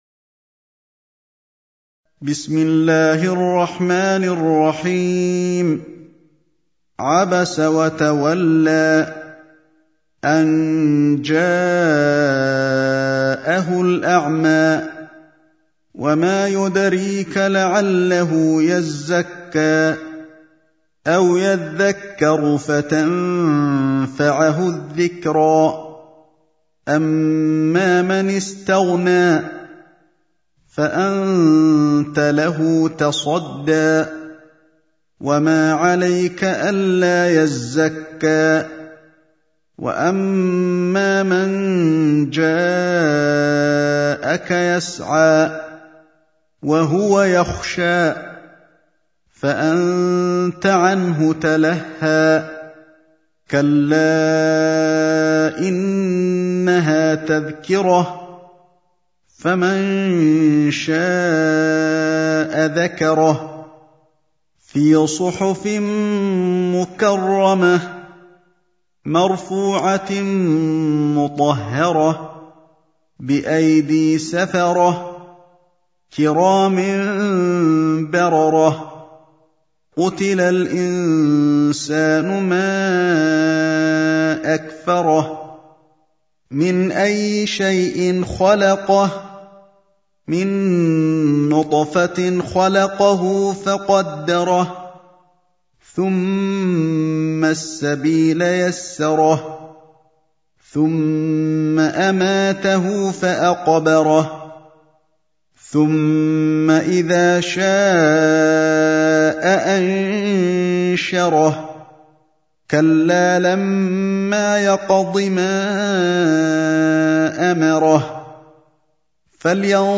سورة عبس | القارئ علي الحذيفي